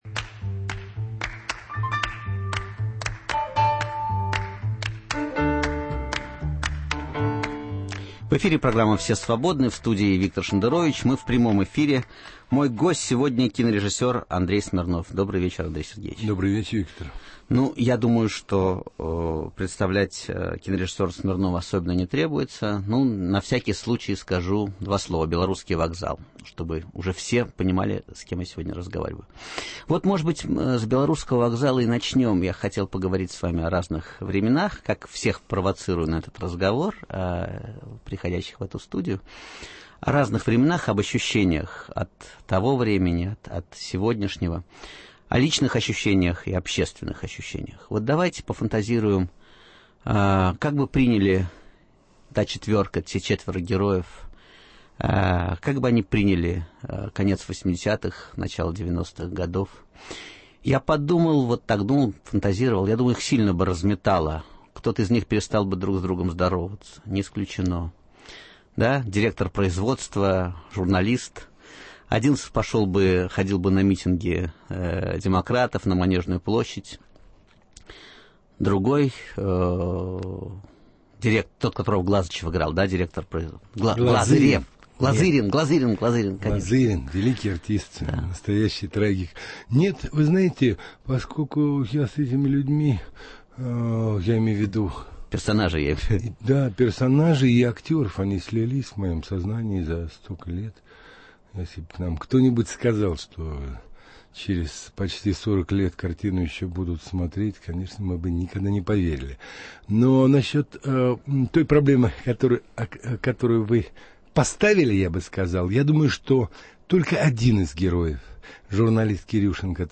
В гостях у Виктора Шендеровича – актер, драматург и режиссер – Андрей Смирнов («Белорусский вокзал», «Чернов», «Дневник его жены»).